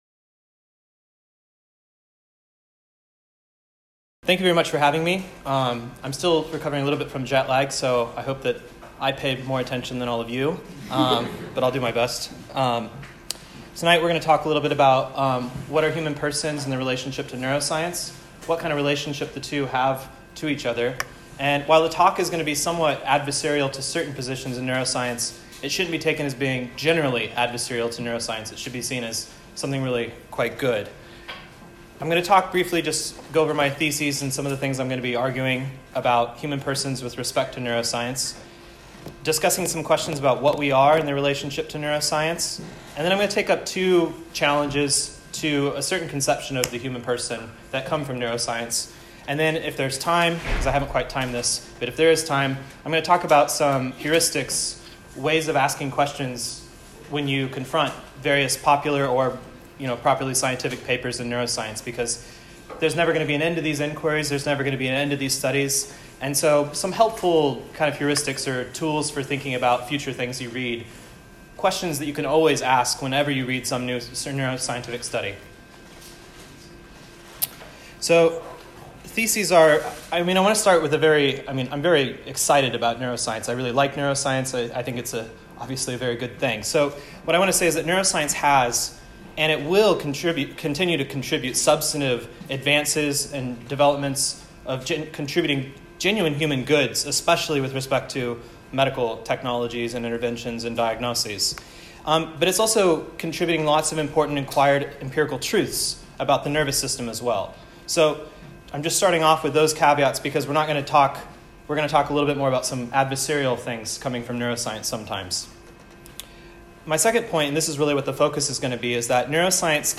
This lecture was given at the University of Texas at Austin on November 19, 2019.